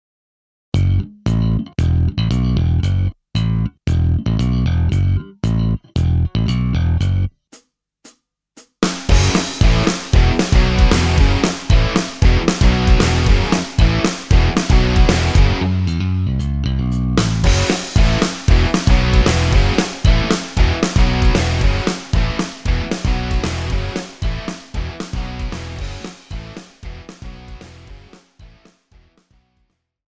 Žánr: Punk
BPM: 229
Key: Fis